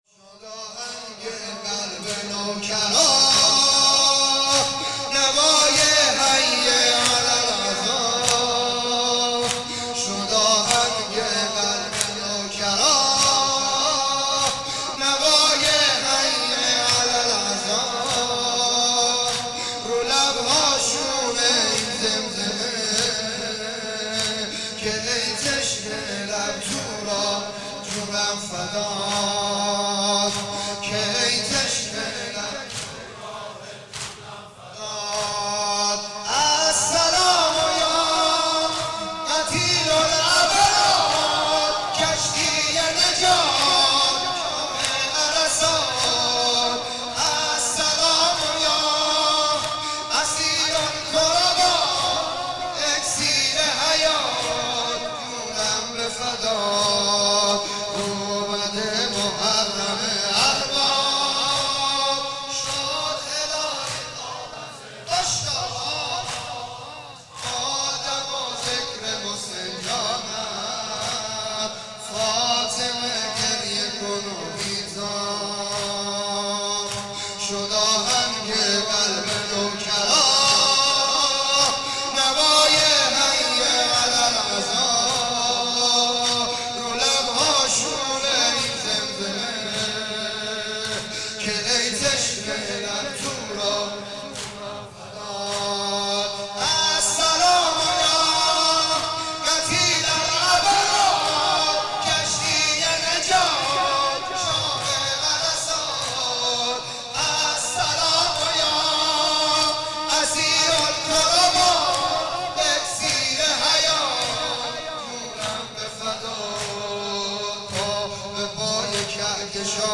شب دوم محرم 1395